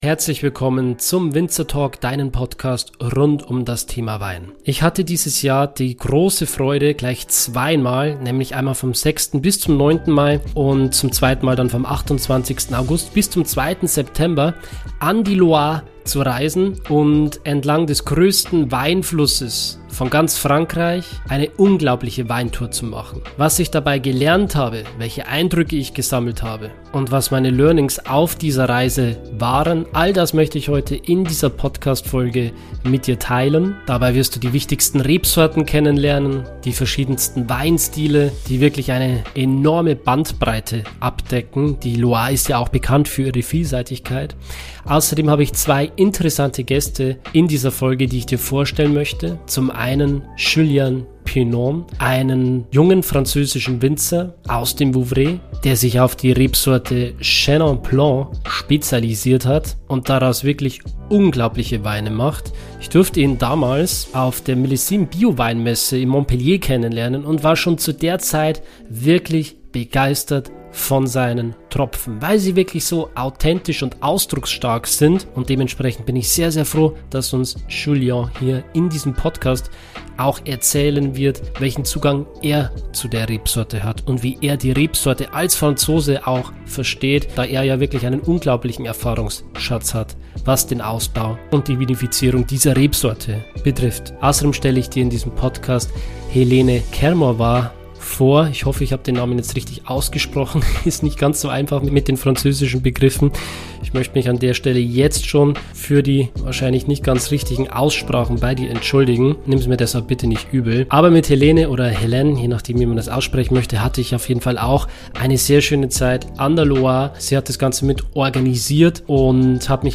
Ich werde mit Winzern von bekannten Weingütern und Experten auf diesem Gebiet sprechen.